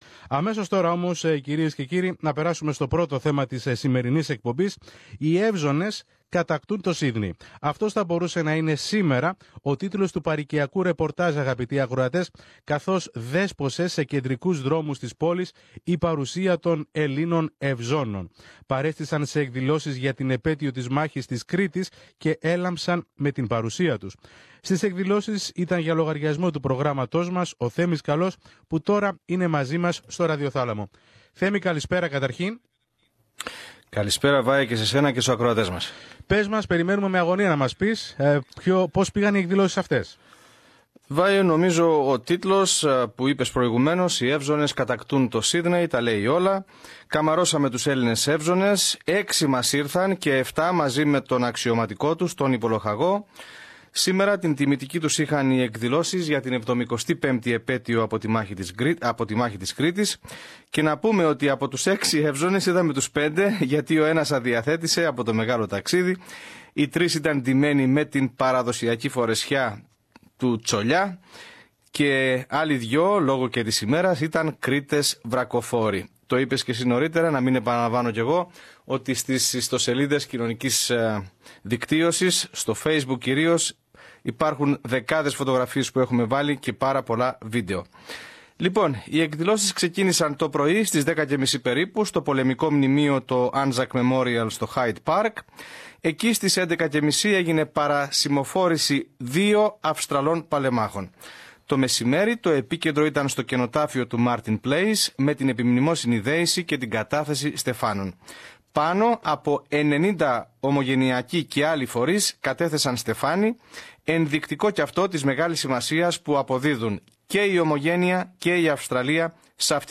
Οι Εύζωνες κατακτούν το Σύδνεϋ. Αυτός θα μπορούσε να είναι σήμερα ο τίτλος του παροικιακού ρεπορτάζ αγαπητοί ακροατές καθώς δέσποσε σε κεντρικούς δρόμους της πόλης η παρουσία των Ελλήνων Ευζώνων. Παρέστησαν σε εκδηλώσεις για την Επέτειο της Μάχης της Κρήτης και τράβηξαν πάνω τους όλα τα φώτα.…